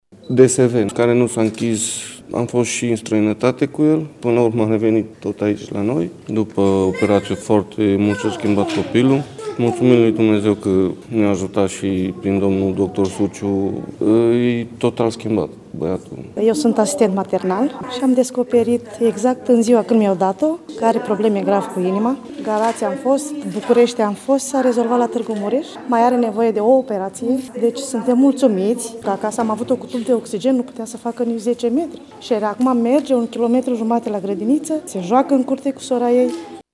Cu această ocazie, 70 de copii salvați în ultimele luni de medici au participat la activități sportive, iar părinții lor au mulțumit încă o dată personalului din Institut: